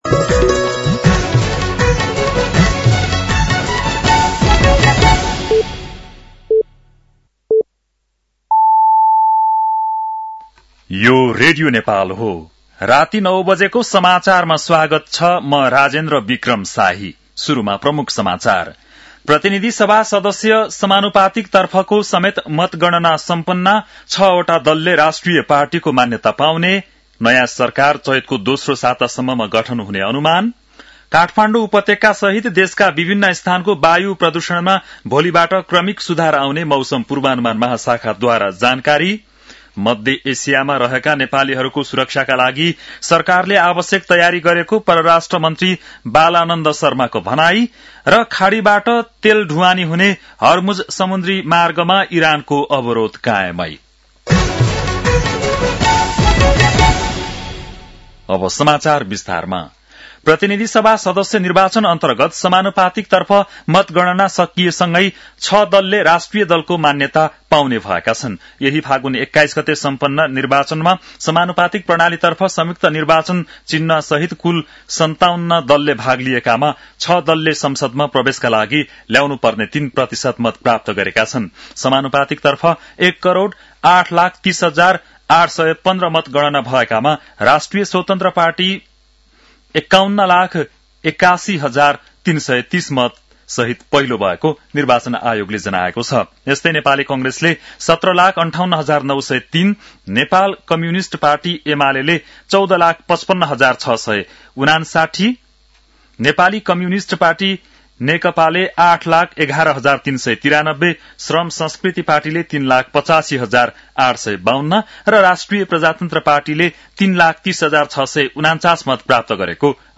बेलुकी ९ बजेको नेपाली समाचार : २७ फागुन , २०८२
9-pm-nepali-news-11-27.mp3